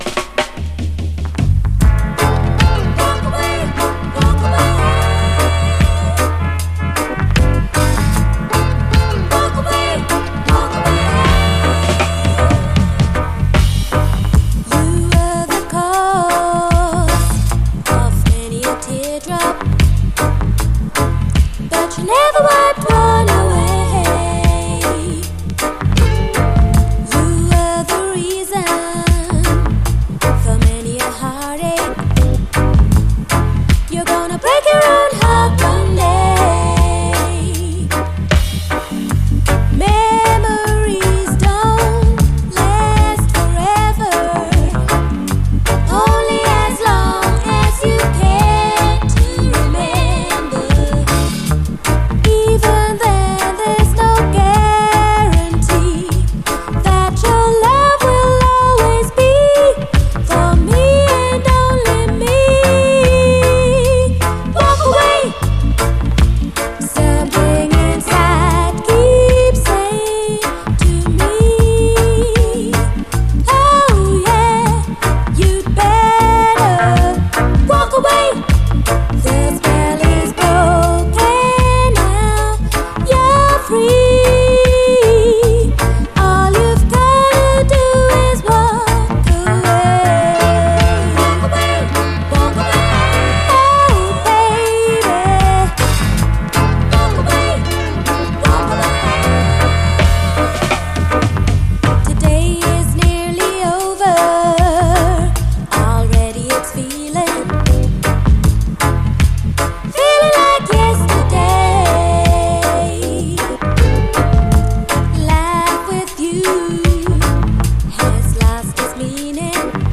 REGGAE
後半のダブまで聴き逃せない！